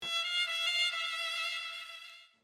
8. Strings FX
Another great fx sound. This string effect one was pulled from a miscellaneous sample pack that I’ve compiled over the many years of making beats.
09-transition-strings-fx.mp3